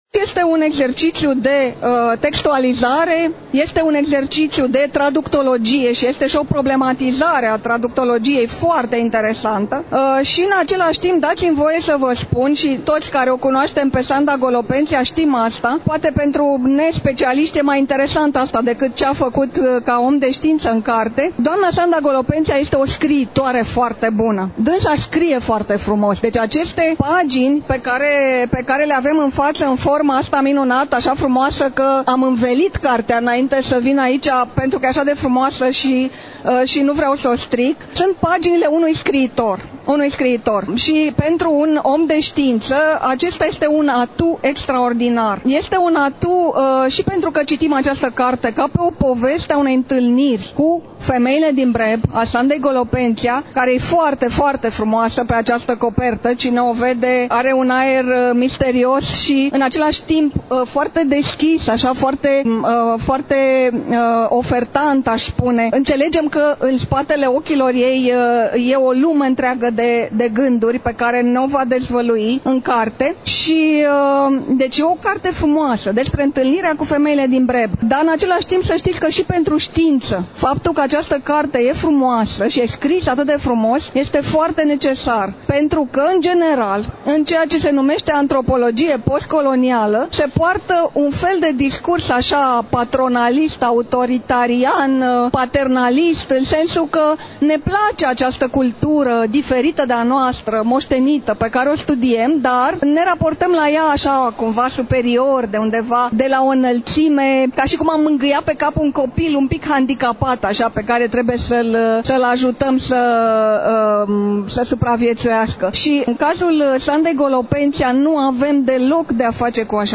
Astăzi, relatăm de la Târgul de Carte Gaudeamus Radio România, ediția a XXIX-a, 7 – 11 decembrie 2022, Pavilionul B2 al Complexului Expoziţional Romexpo, București.